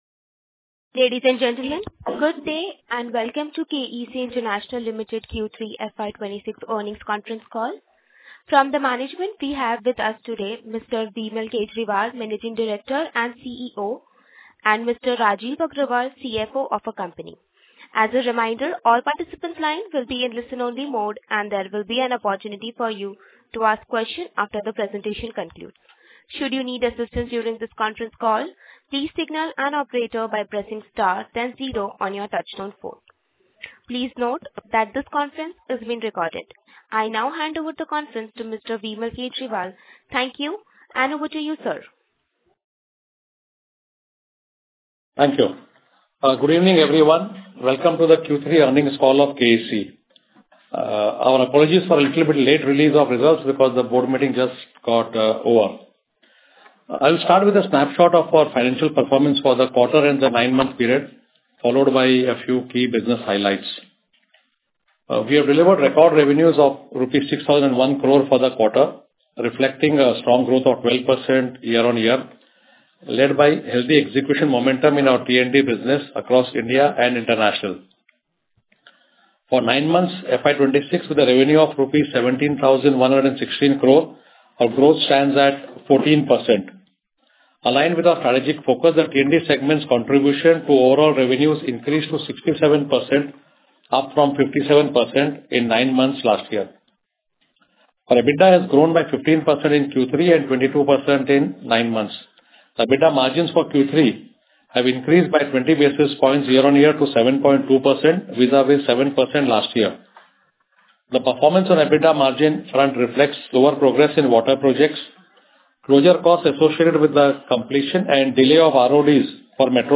KEC Q3 FY26 Earnings Call Audio.mp3